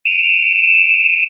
SFX_silbato.wav